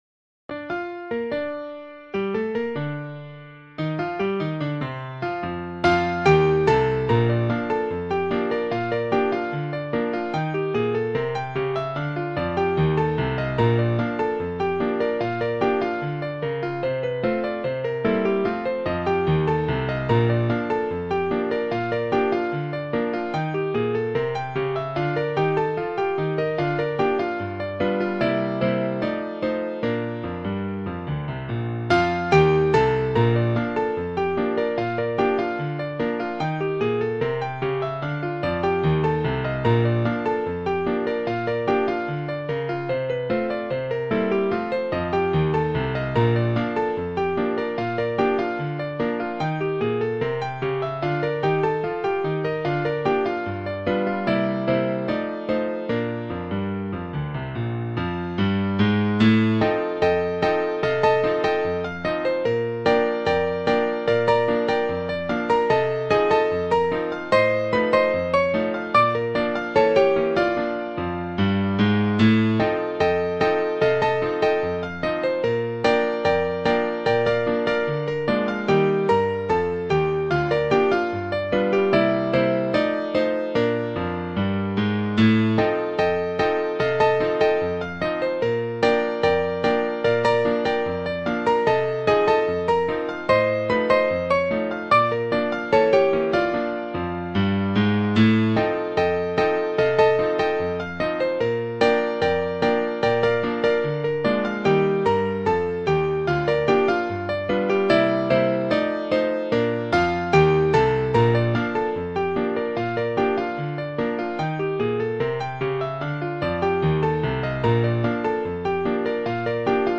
Ragtime Sheet Music